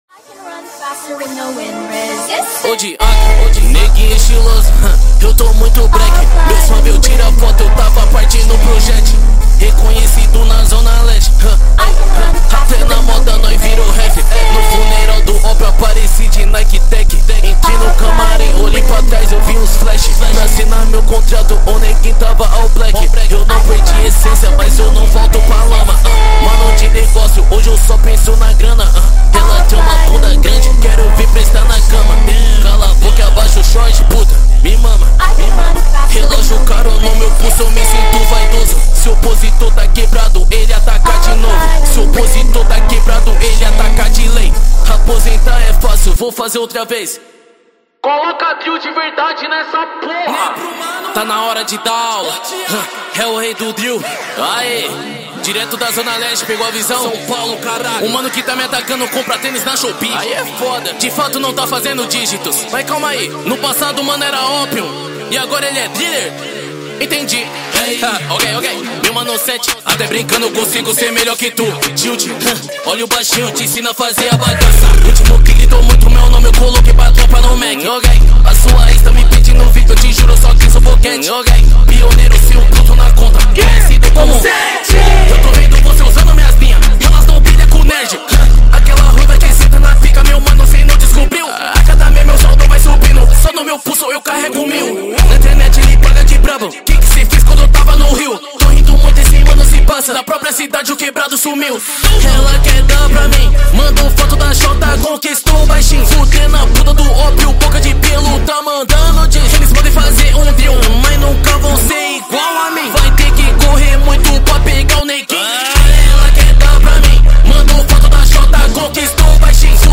2025-02-08 19:28:46 Gênero: Trap Views